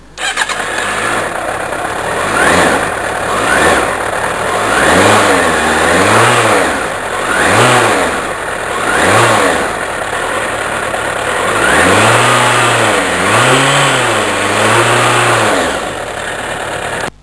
Vous allez trouver tous les sons de bécanes ici, ça va du bruit d'échappement quelconque aux moteurs de sportives en furie, je vous laisse découvrir...